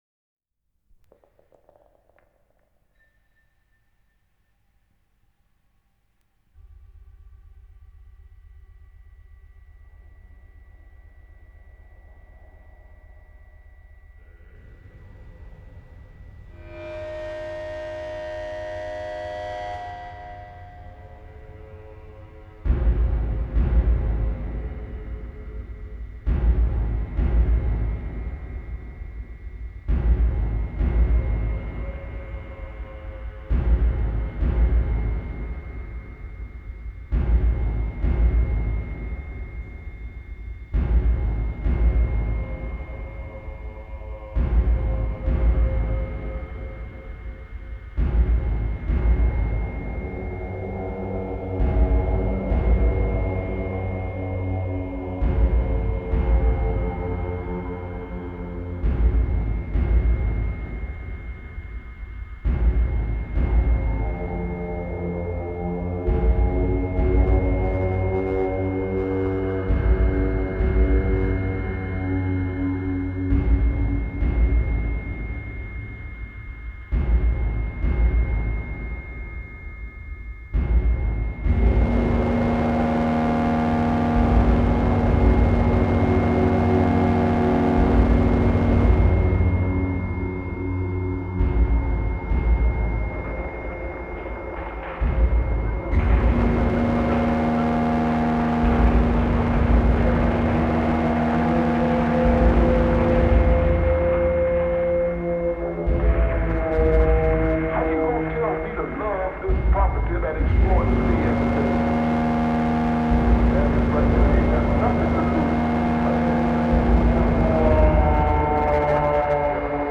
Dark Ambient & Death Industrial